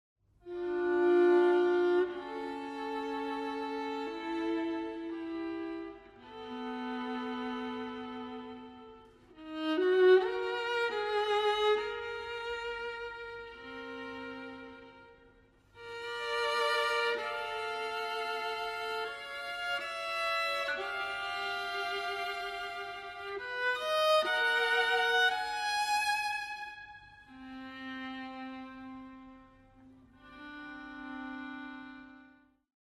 Sonata for solo viola op. 92, no. 3